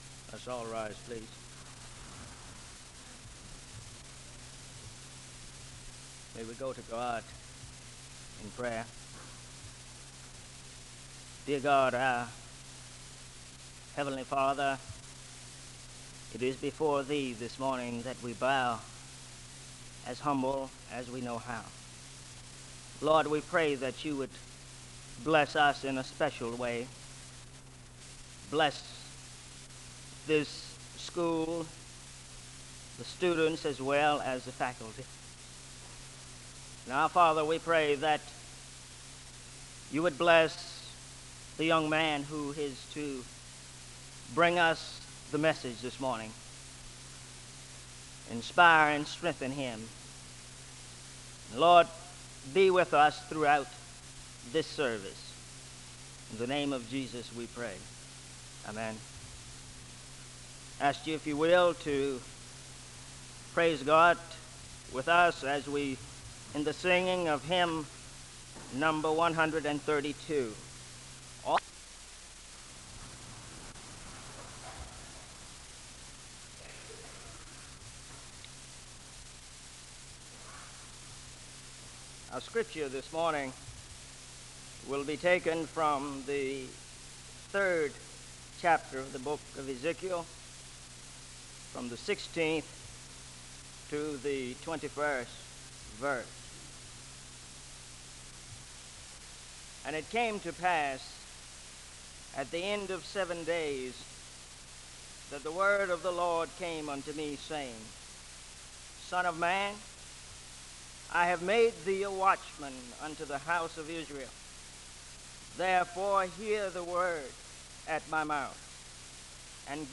Accueil SEBTS Chapel